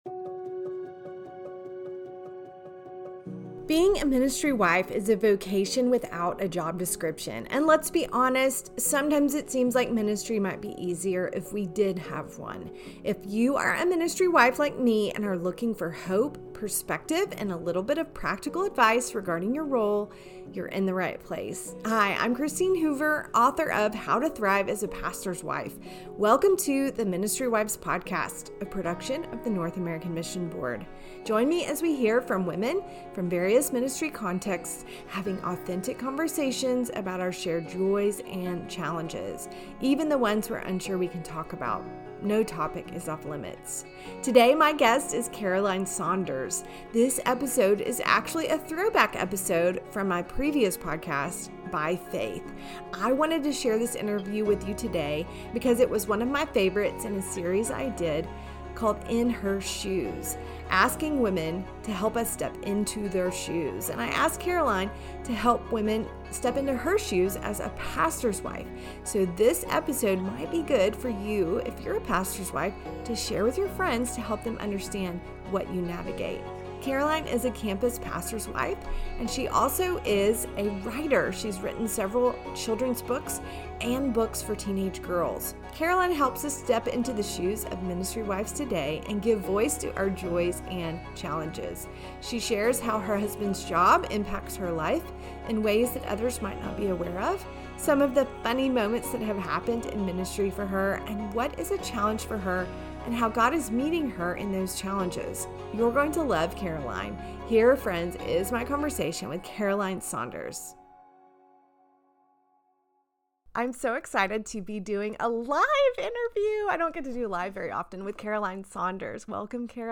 In Her Shoes: A Pastor’s Wife (an interview